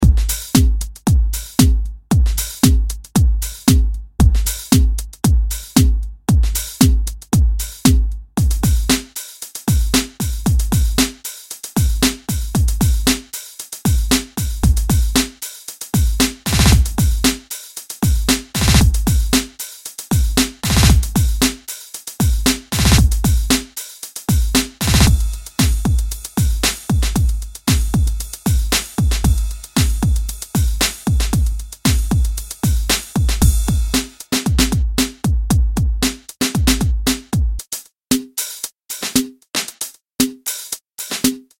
400 dance floor ready drum loops on 4 different
kits. Perfect four-on-the-floor and more for
house, techno, disco, and any groove that needs
DanceBeatsDemo.mp3